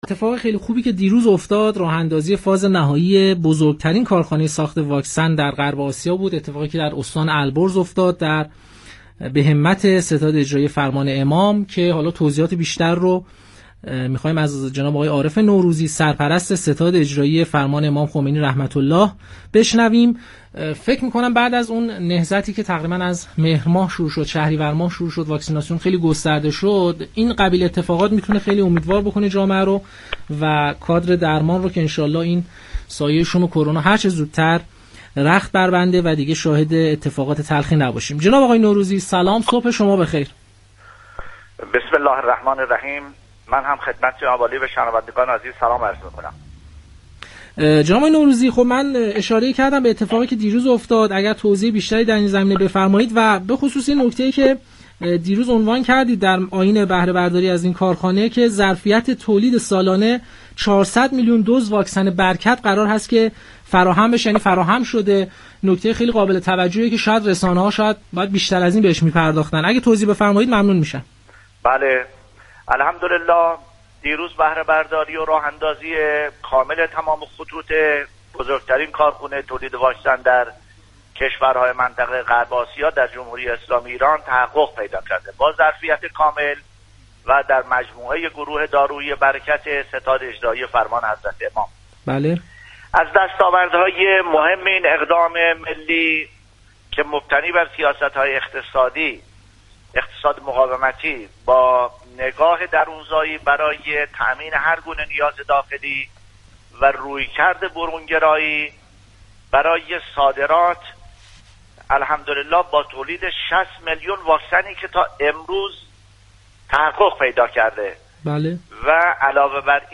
به گزارش پایگاه اطلاع رسانی رادیو تهران، عارف نوروزی سرپرست ستاد اجرایی فرمان حضرت امام خمینی (ره)در گفتگو با پارك‌شهر رادیو تهران با اعلام بهره‌برداری و راه‌اندازی تمام خطوط بزرگترین كارخانه تولید واكسن خاورمیانه در استان البرز گفت: این كارخانه با ظرفیت كامل و در مجموعه گروه دارویی "بركت" ستاد اجرایی فرمان امام خمینی (ره) راه اندازی شده است.